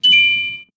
Pingding
alert computer ding game ping scifi sound effect free sound royalty free Sound Effects